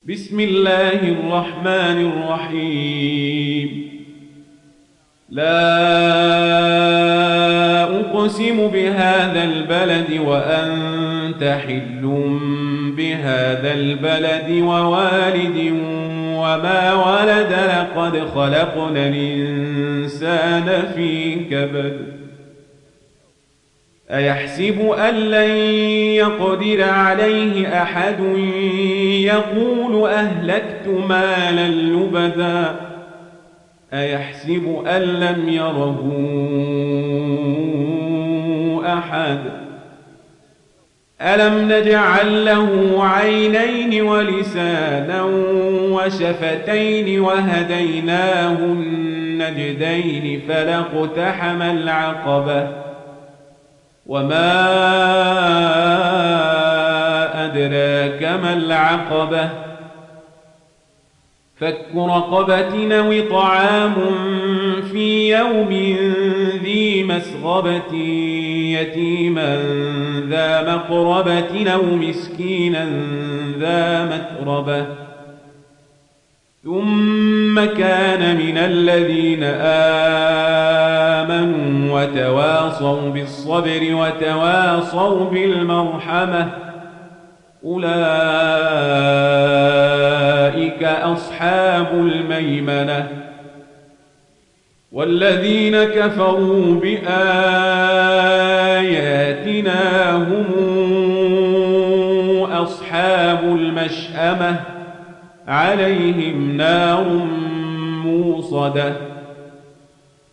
Surah Al Balad Download mp3 Omar Al Kazabri Riwayat Warsh from Nafi, Download Quran and listen mp3 full direct links